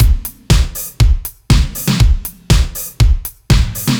Index of /musicradar/french-house-chillout-samples/120bpm/Beats
FHC_BeatC_120-02.wav